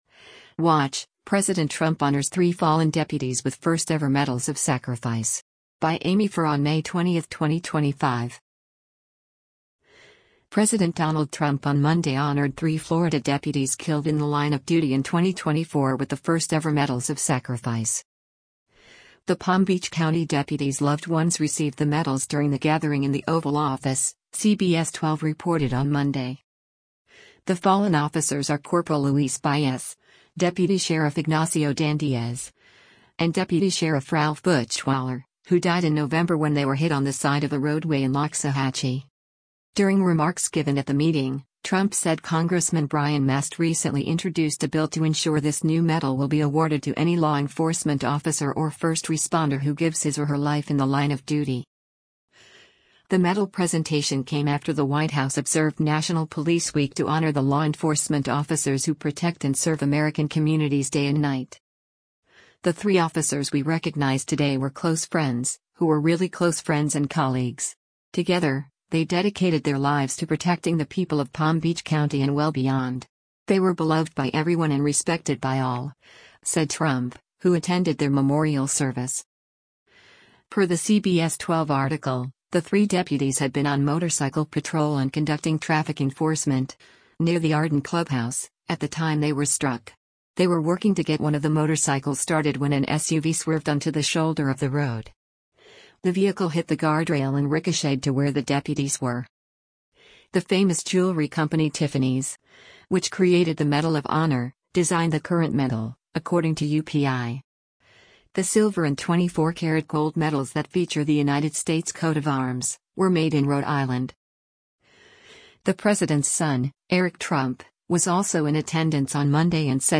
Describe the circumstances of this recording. The Palm Beach County deputies’ loved ones received the medals during the gathering in the Oval Office, CBS 12 reported on Monday.